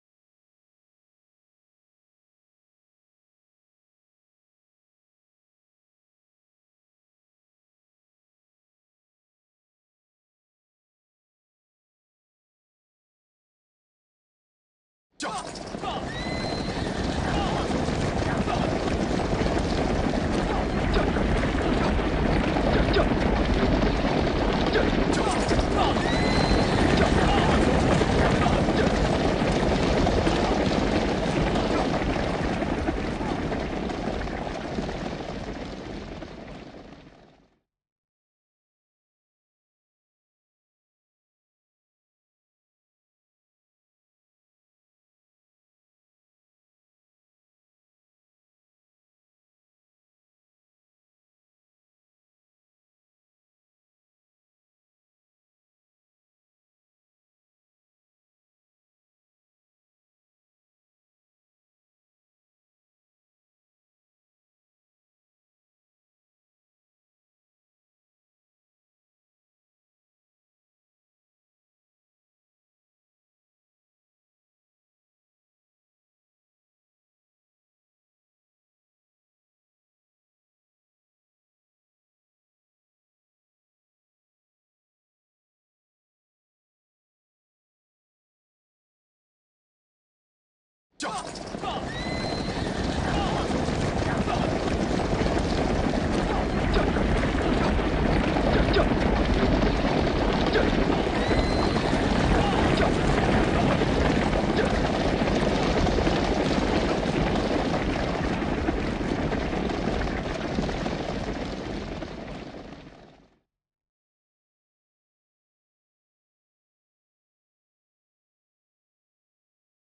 SFX_Scene06_Horse.ogg